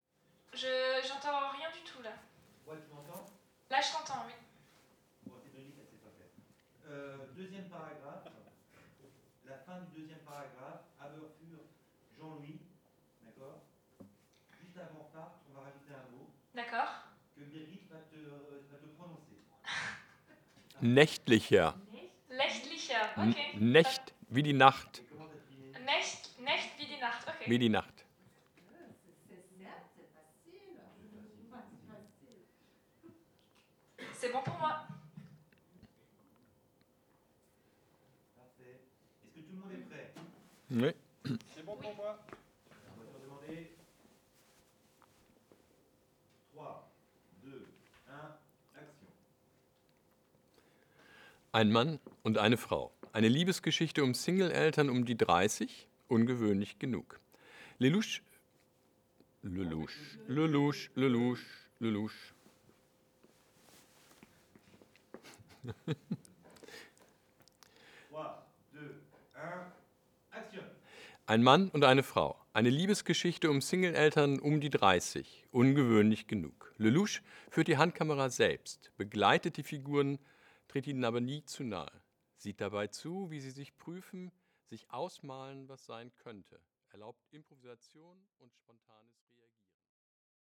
Strasbourg, Arte - CMIT5U / SD664 / Nagra Lino